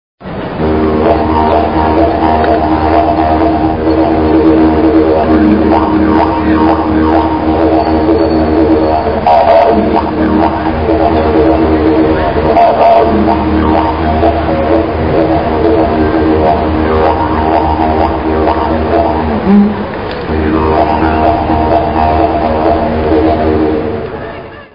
Didgeridoo nella baia di Sydney (Mp3 62 kbytes)
didgeridoo.mp3